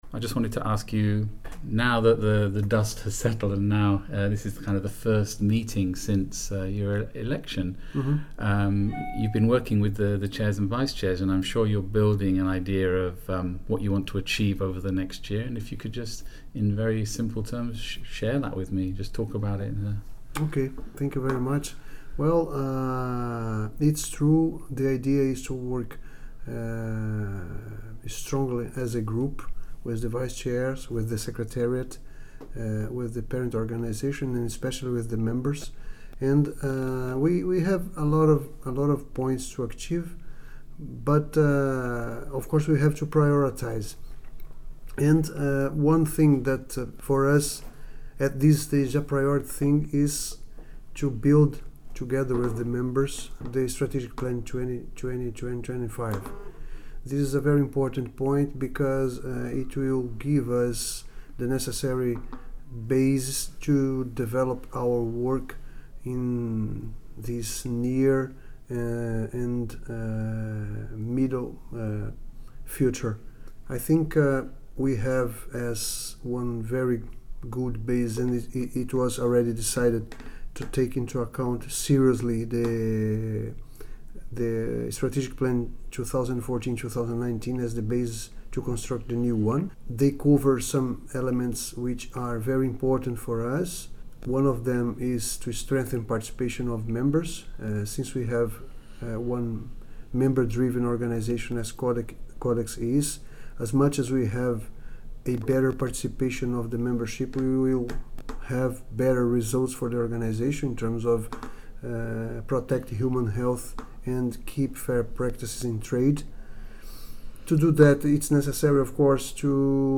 As the Executive Committee of the Codex Alimentarius Commission began its three-day meeting in Rome, new Chair, Mr Guilherme da Costa, spoke of the goals for Codex under his leadership.
Codex-Chair-interview-Sept2017.mp3